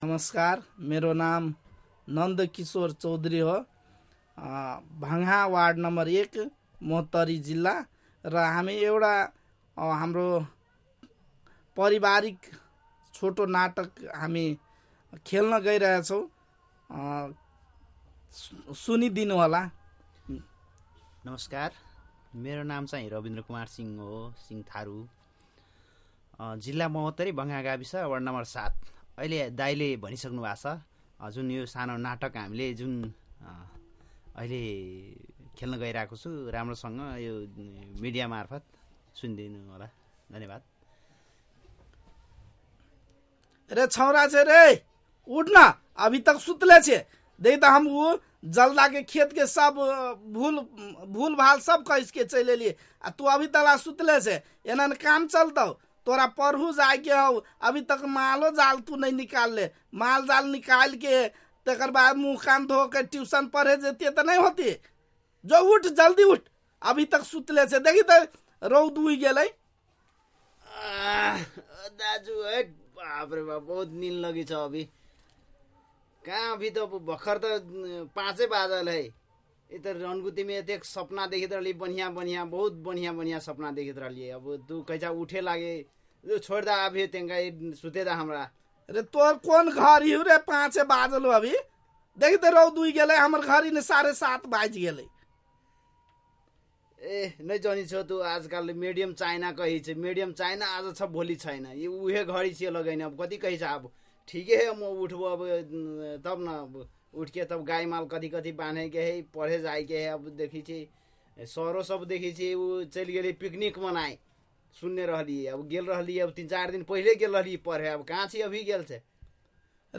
नाटक | मध्य-पूर्वीया थारू